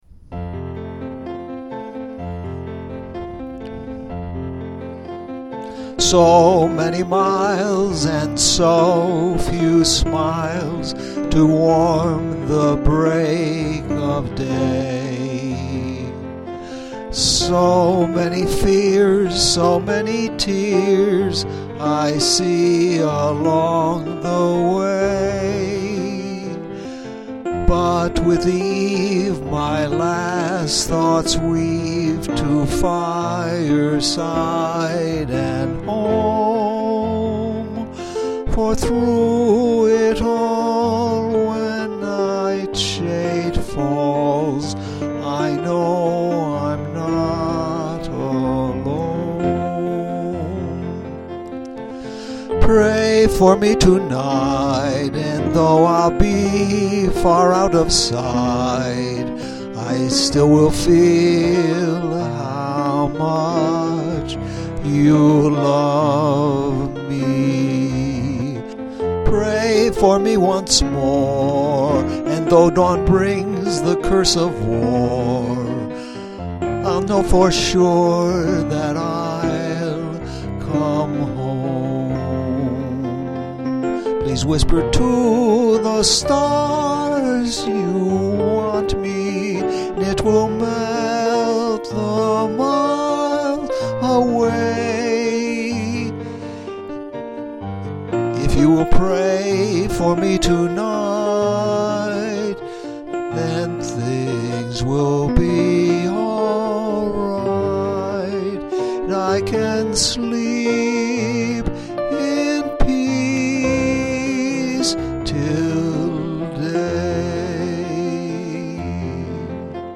A soldier’s ballad ….